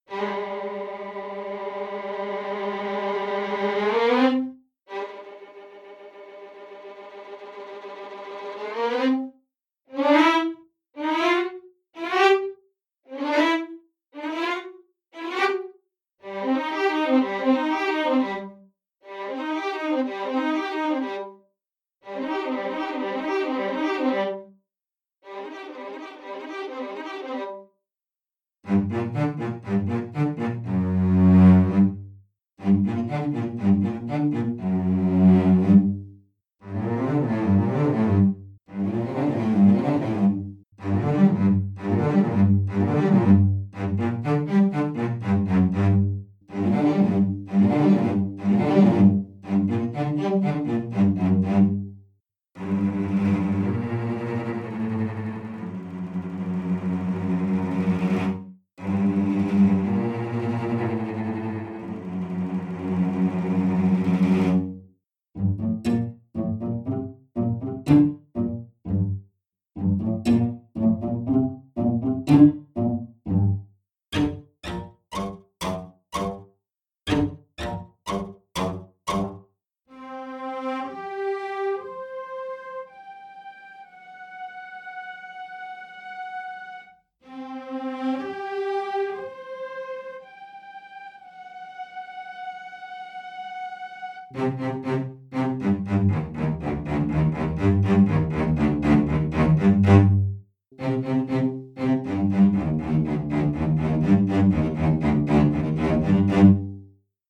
In a way the new patches sound weirder to me. Here I did some violin and cello ensemble (dry) comparison. Motifs are first the old ones and directly after that the new ones.